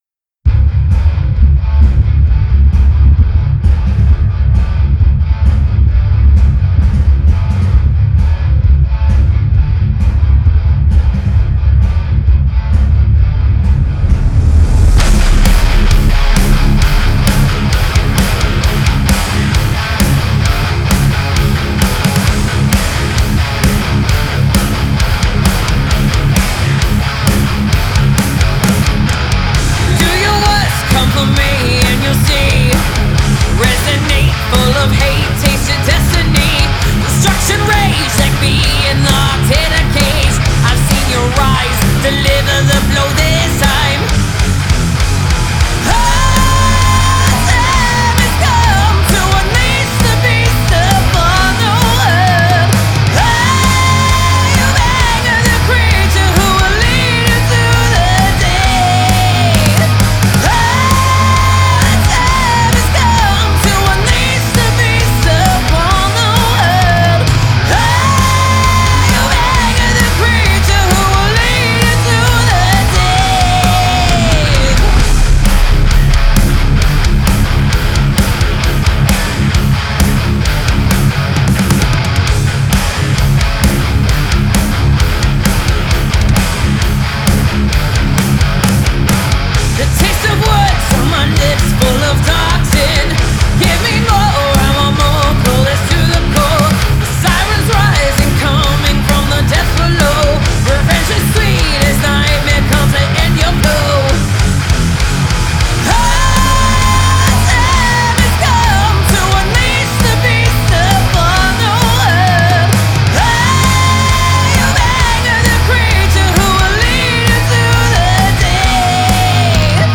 female-fronted hard rock and groove metal band
visceral riffs, anthemic hooks